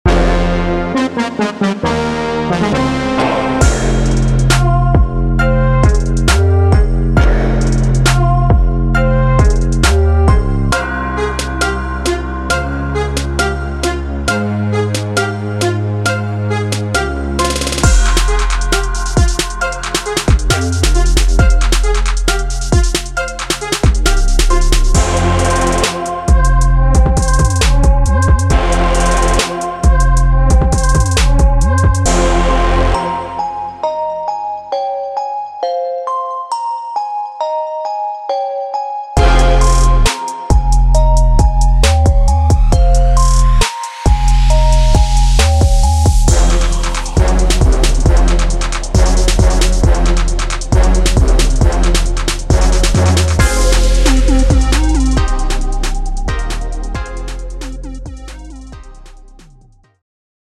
Niche